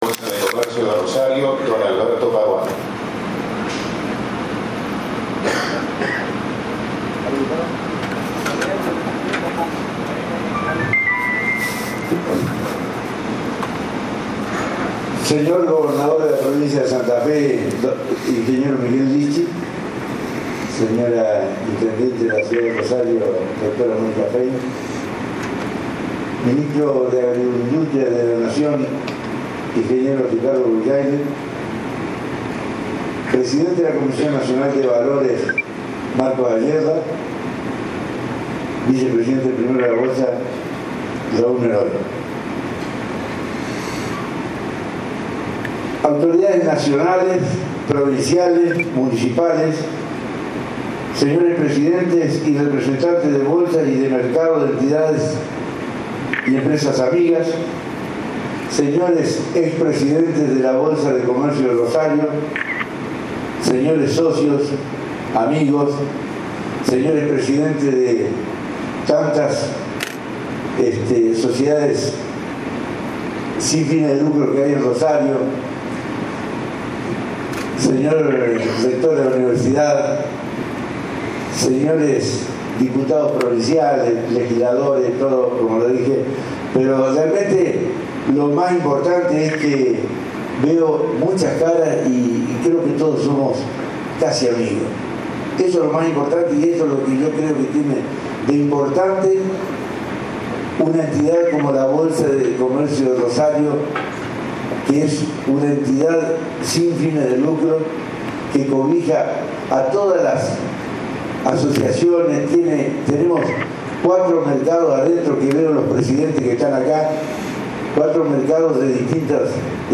Charla en el auditorio